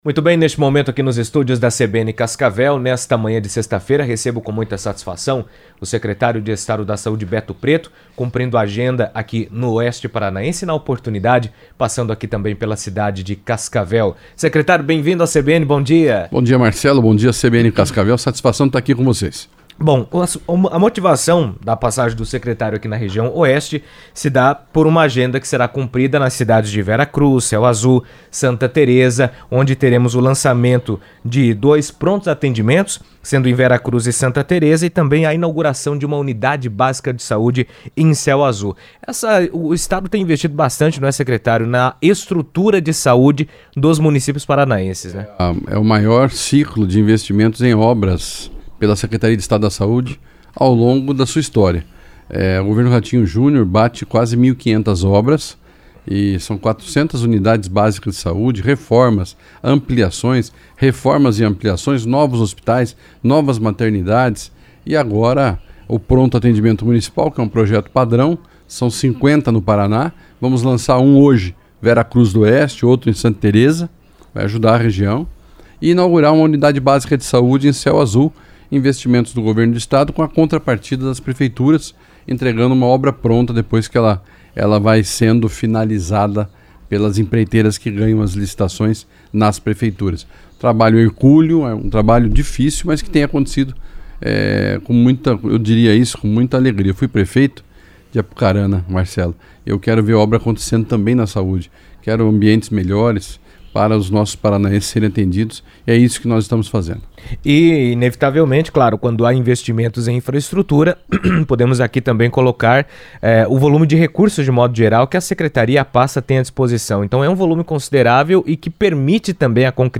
O Secretário de Estado da Saúde, Beto Preto, falou na CBN sobre o programa de expansão da rede pública de saúde do Paraná, destacando a descentralização dos serviços e a ampliação do atendimento hospitalar nos municípios. Durante agenda na região oeste, ele anunciou a construção de novos Prontos Atendimentos Municipais (PAMs) em Santa Tereza do Oeste e Vera Cruz do Oeste, com investimento de R$ 7 milhões cada, além da inauguração de uma Unidade Básica de Saúde (UBS) em Céu Azul.